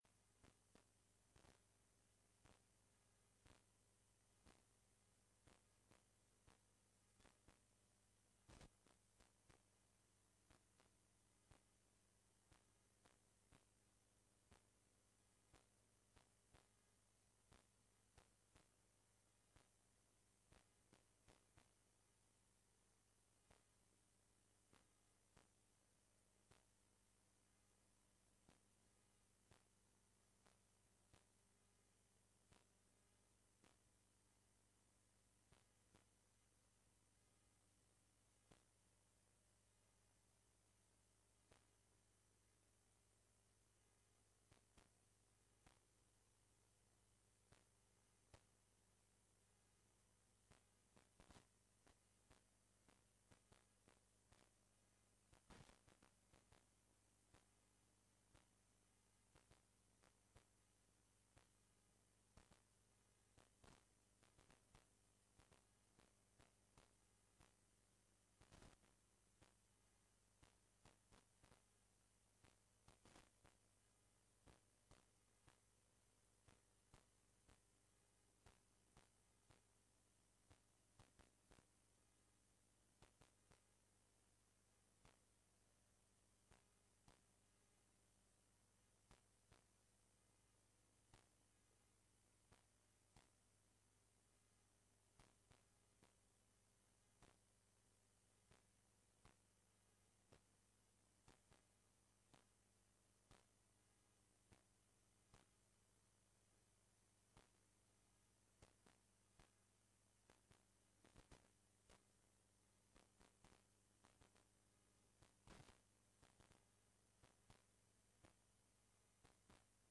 Download de volledige audio van deze vergadering
Locatie: Stadhuis Raadzaal